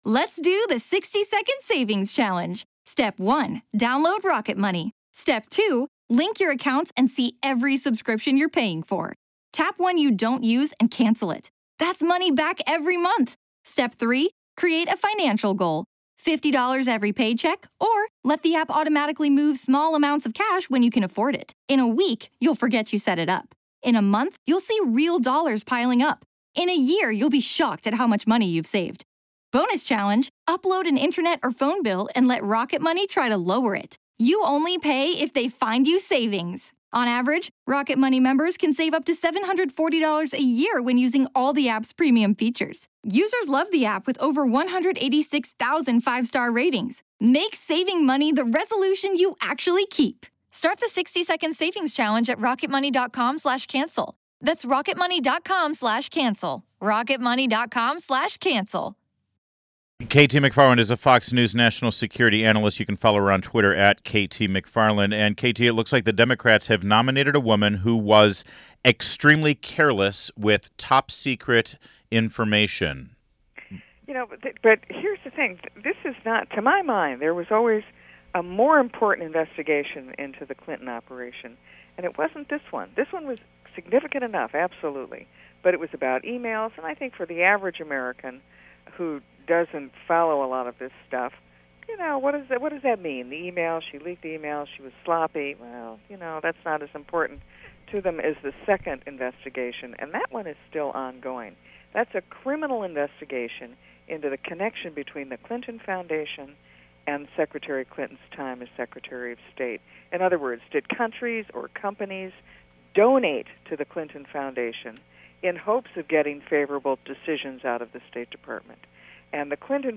WMAL Interview KT McFarland 07.06.16